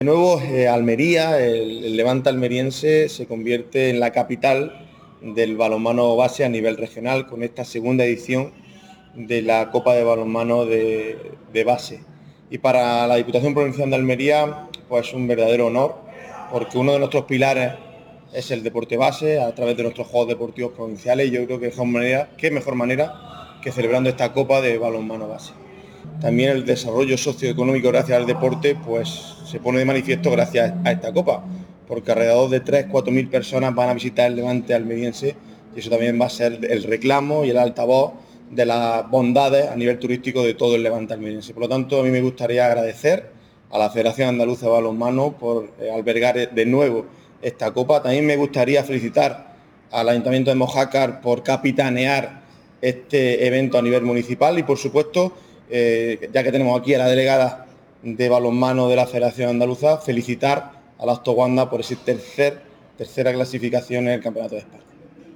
El Pabellón Moisés Ruiz de la Diputación ha albergado esta mañana la presentación de la II Copa de Andalucía de Balonmano Base 2024-2025.